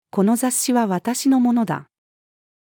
この雑誌は私の物だ。-female.mp3